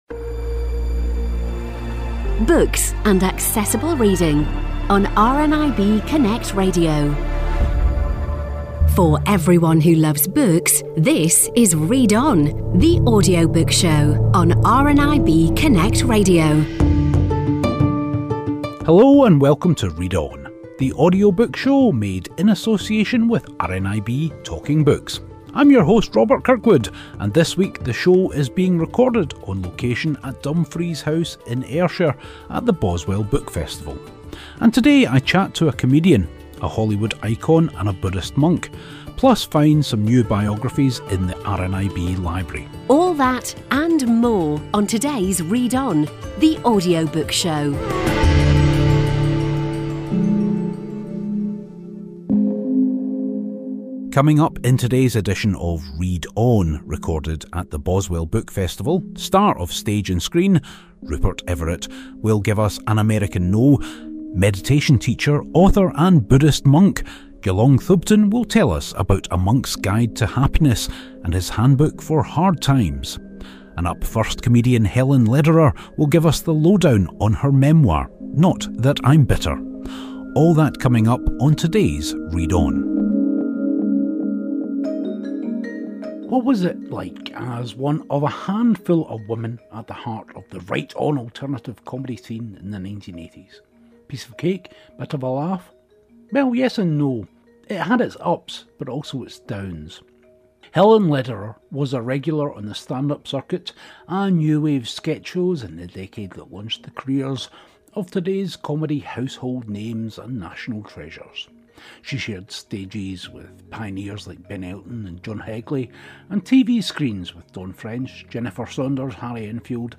In today's edition of Read On, we're on location at Dumfries House at this year's Boswell Book Festival.